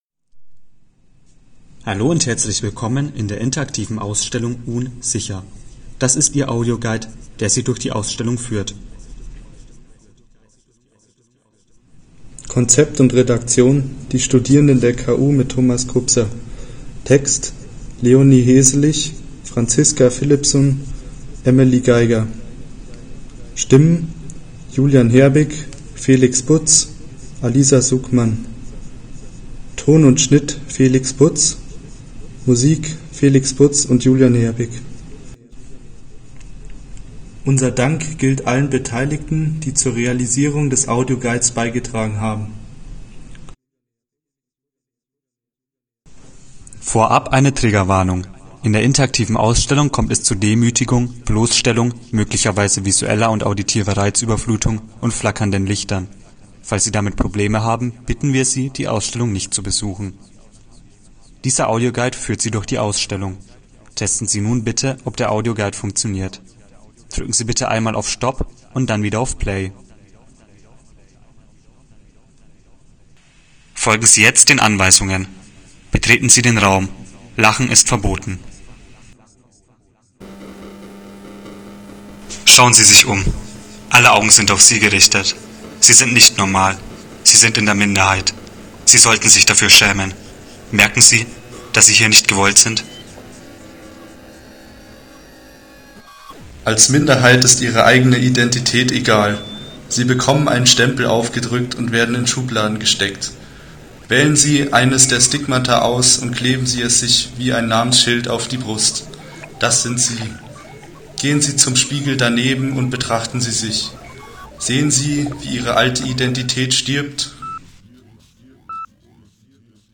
Audioguide-UN_SICHER.mp3